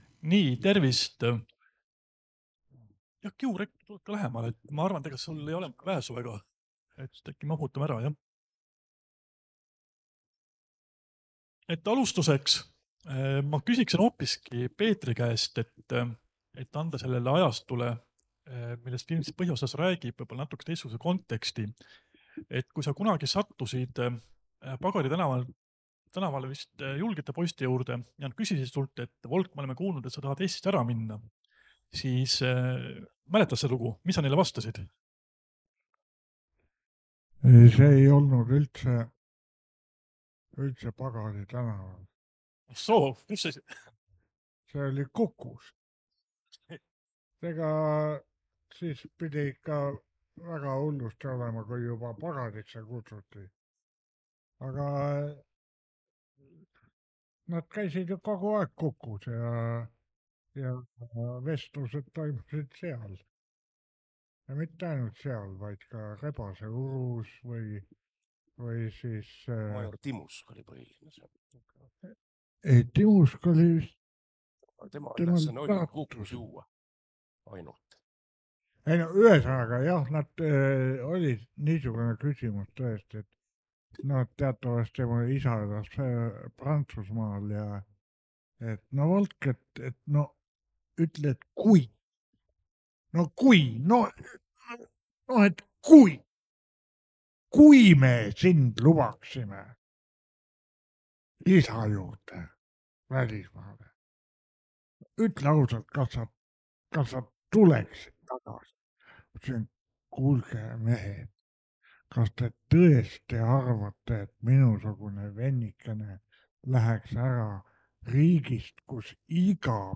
14. septembril vallutas Elektriteatri saali vägagi muhe seltskond.
NB! Vestluse salvestus sisaldab sisurikkujaid.